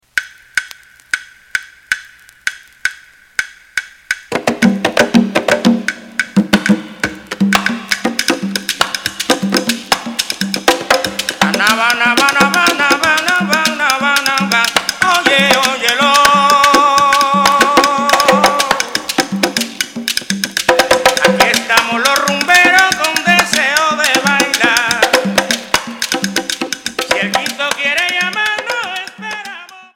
Темп: 116 bpm